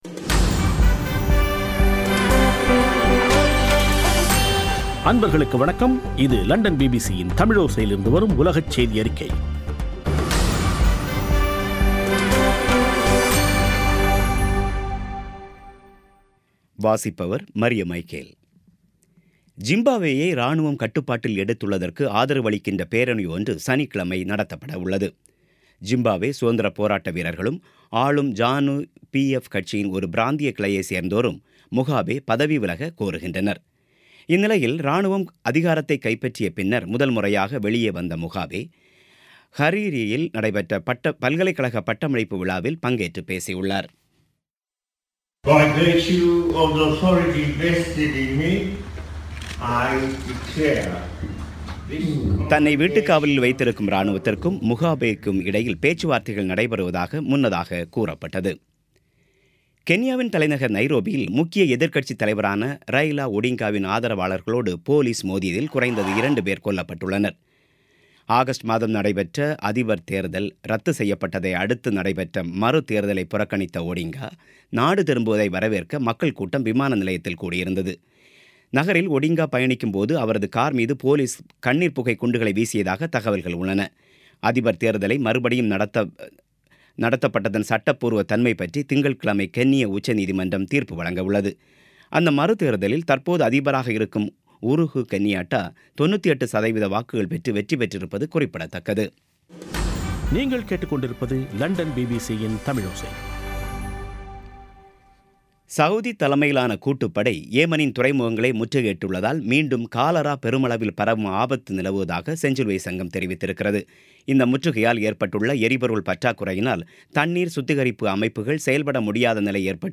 பிபிசி தமிழோசை செய்தியறிக்கை (17/11/2017)